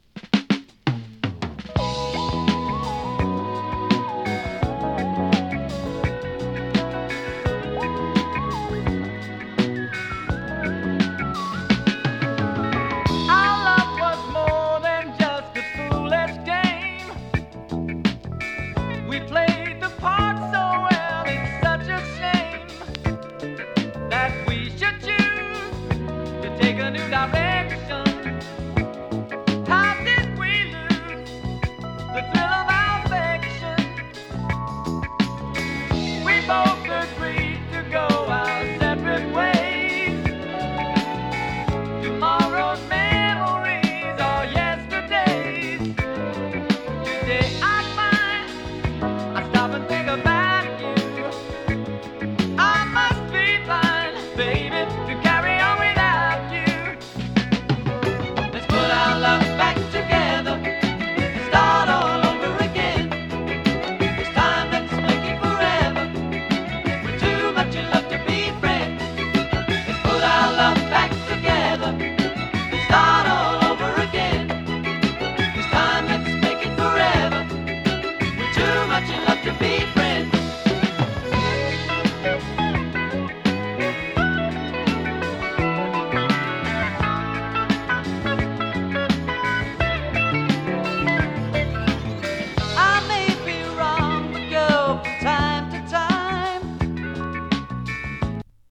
AOR ブリティッシュファンク 王道ソウル フリーソウル